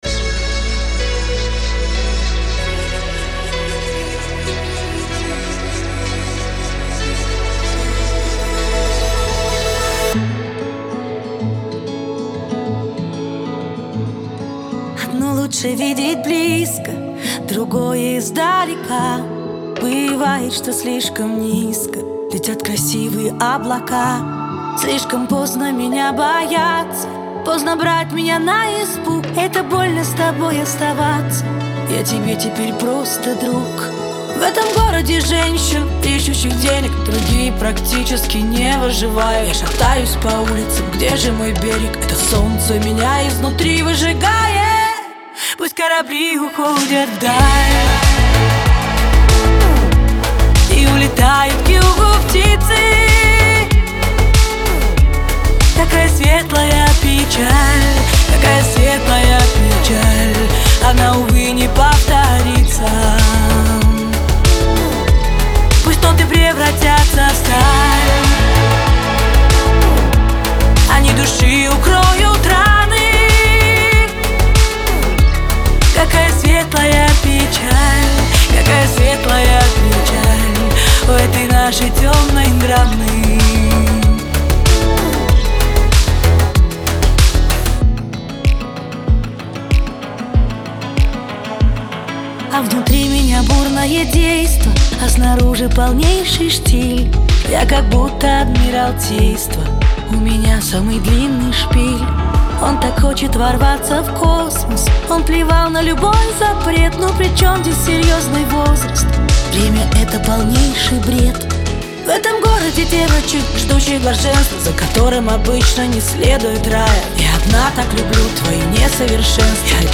Грустные
Трек размещён в разделе Русские песни / Поп / 2022.